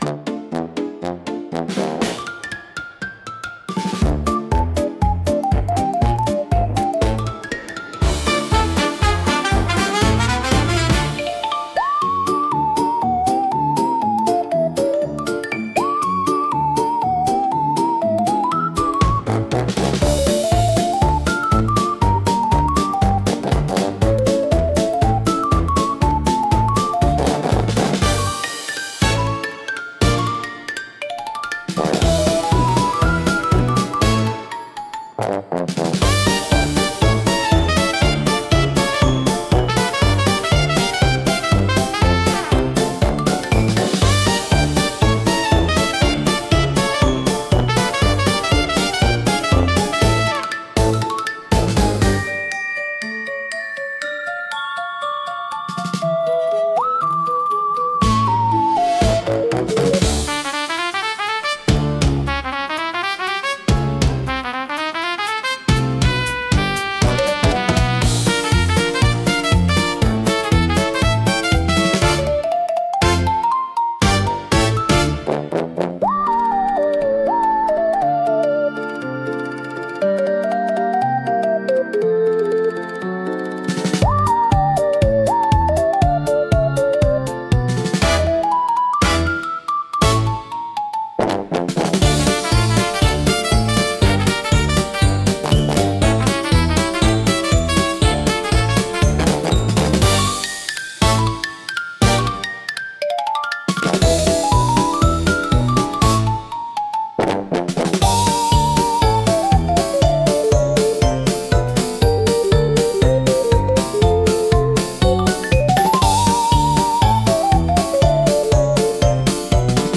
聴く人に軽やかで楽しい気分を届け、明るく気楽な空気を作り出します。